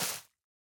latest / assets / minecraft / sounds / block / moss / break2.ogg